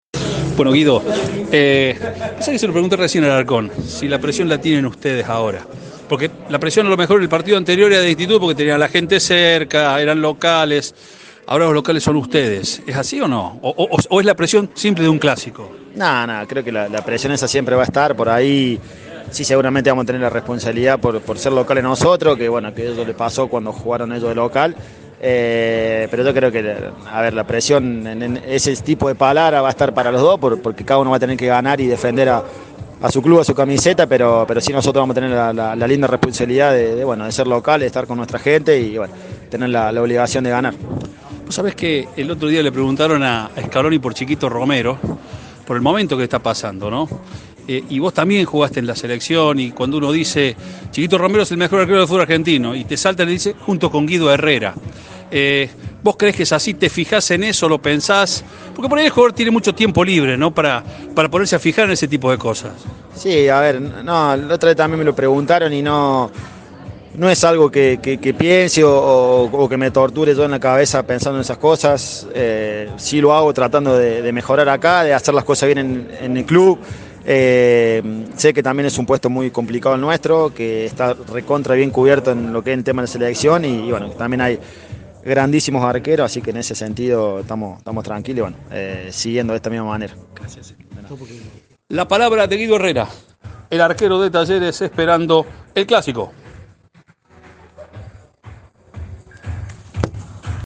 El arquero de Talleres habló con Cadena 3 de cara al clásico del sábado ante Instituto.
Guido Herrera habló con la mente en el clásico.